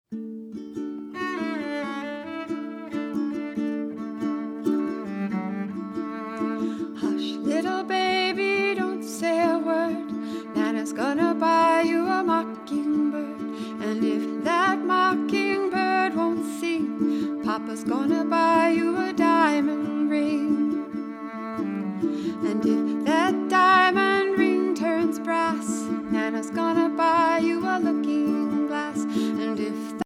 Lullabies from Then, Now and Forever